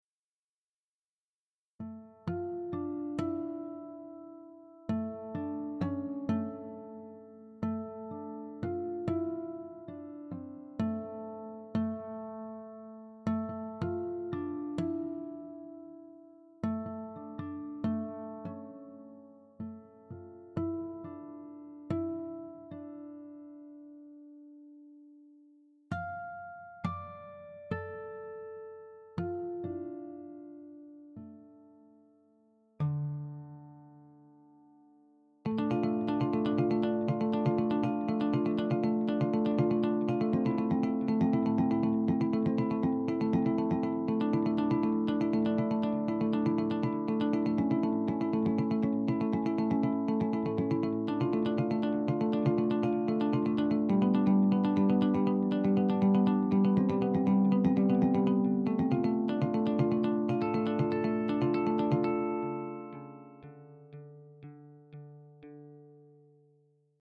Dabei handelt es sich um eine Reihe virtueller Gitarrensoftware, die den Namen GuitarMonics trägt. Angeboten werden: eine Akustikgitarre, eine E-Gitarre und ein E-Bass.
Der Sound: Der Sound ist schon ziemlich speziell und erinnert mich bei allen drei Instrumenten eher an eine Harfe, als an eine Gitarre.
Bei hohen Pegeln verzerrt der Klang ziemlich schnell.